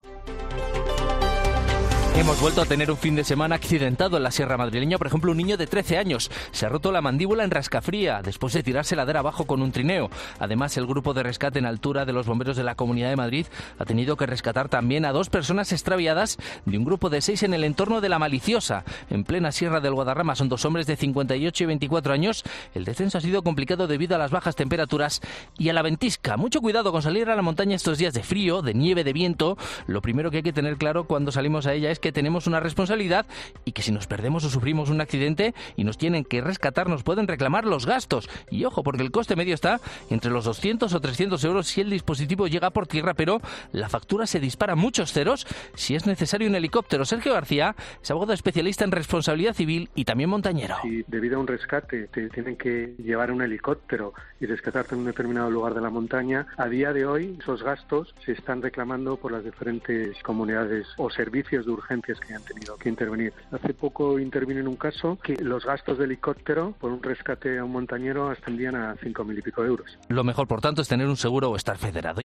'COPE Madrid' habla con un abogado especializado en la Responsabilidad Civil de los accidentados en este tipo de casos